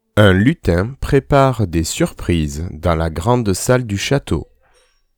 Les dictées du groupe C2 :